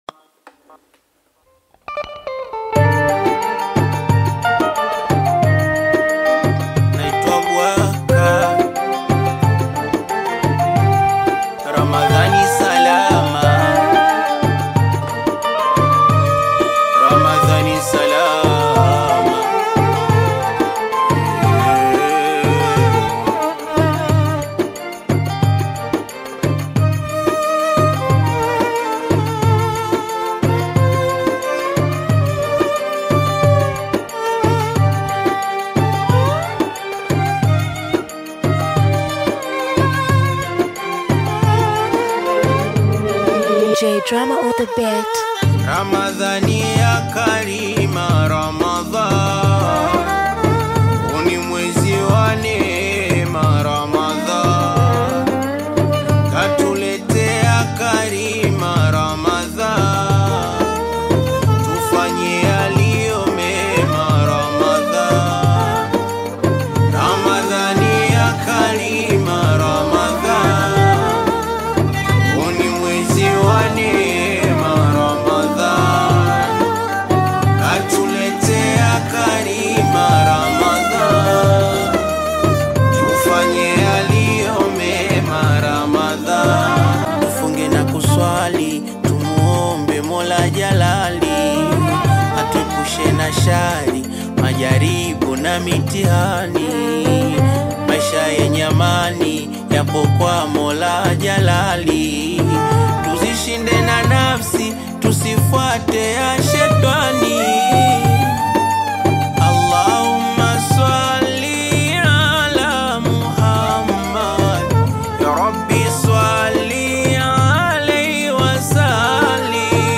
KASWIDA